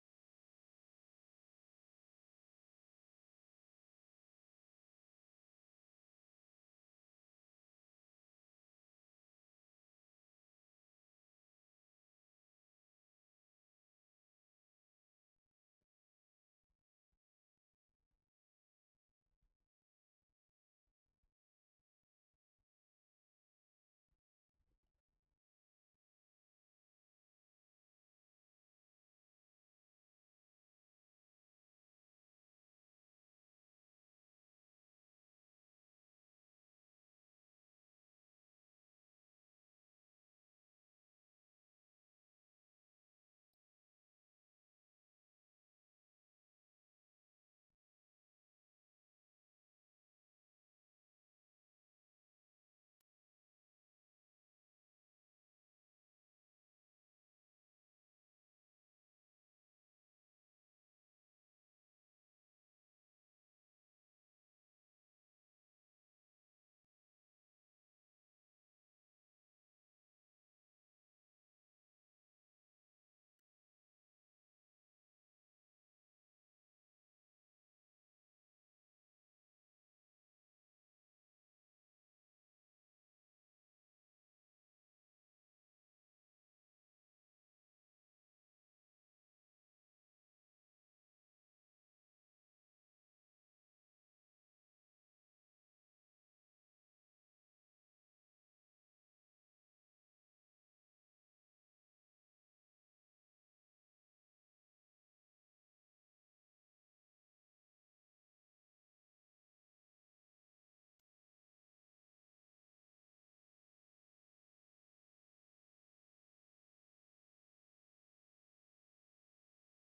Matthew 7:13-29 Service Type: audio sermons « Bad Ideas